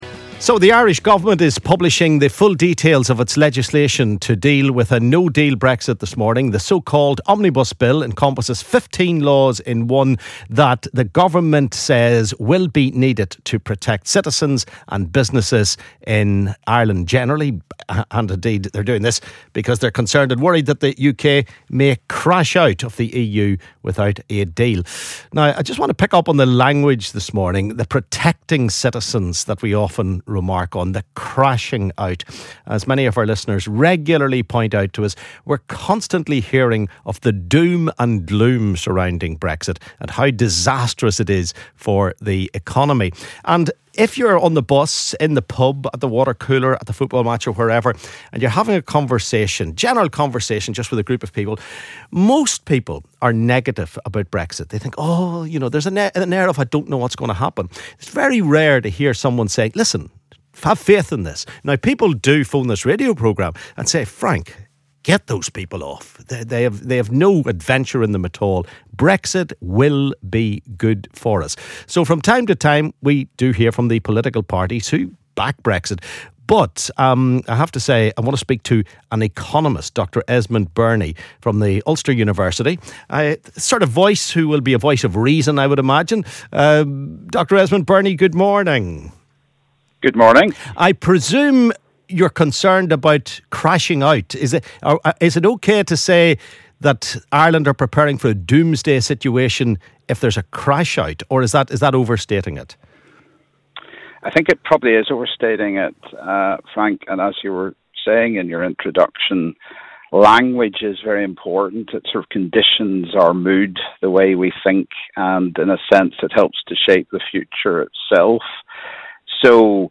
LISTEN ¦ With an array of negative Brexit headlines, we ask: What are the positives of Brexit on the economy? Leading economist Dr Esmond Birnie speaks